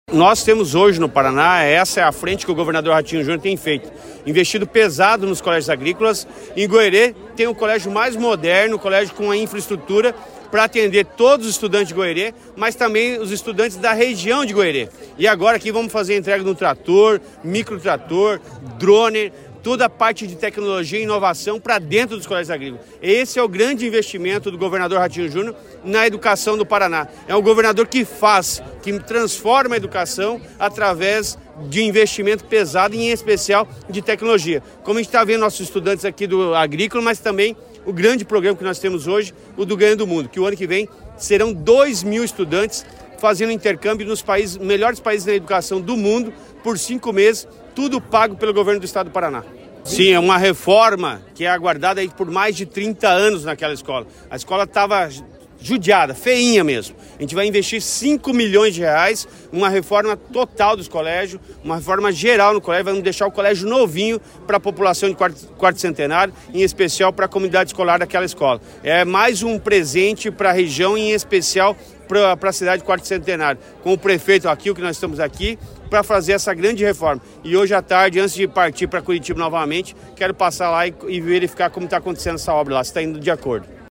Sonora do secretário da Educação, Roni Miranda, sobre a entrega de equipamentos para 11 colégios agrícolas e nova unidade em Goioerê